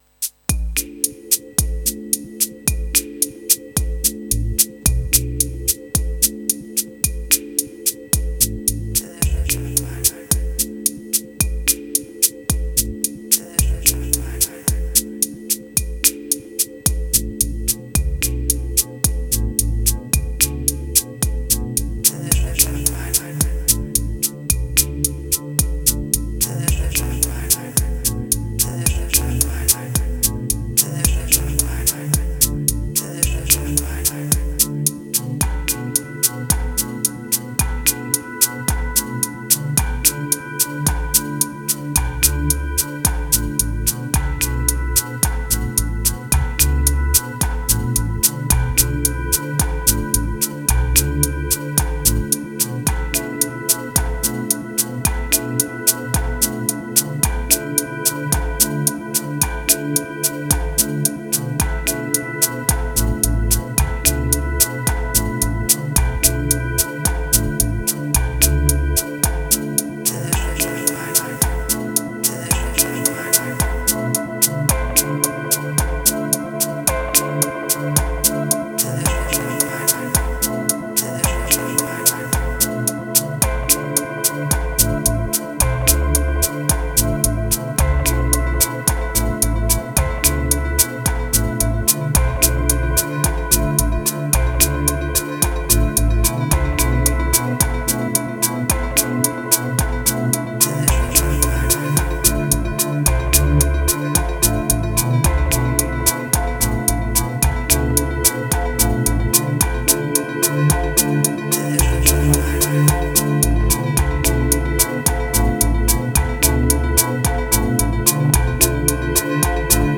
571📈 - 95%🤔 - 55BPM🔊 - 2021-10-13📅 - 785🌟